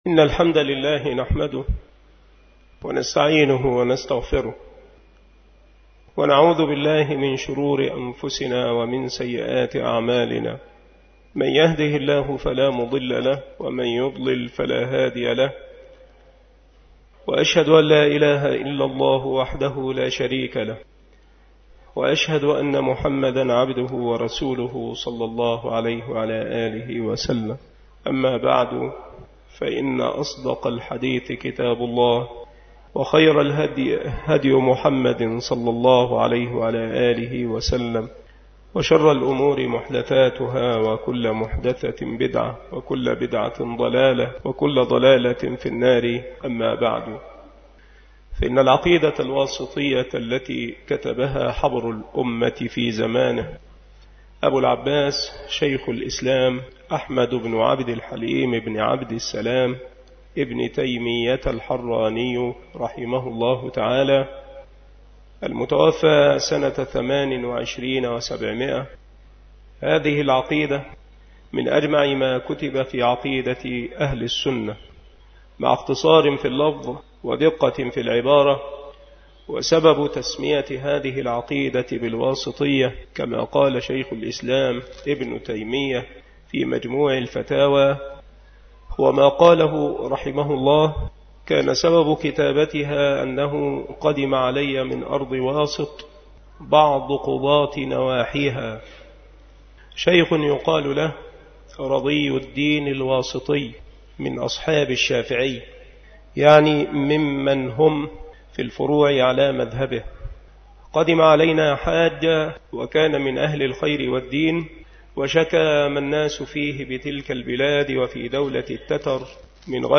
مكان إلقاء هذه المحاضرة بالمسجد الشرقي بسبك الأحد - أشمون - محافظة المنوفية - مصر عناصر المحاضرة : قراءة متن العقيدة الواسطية.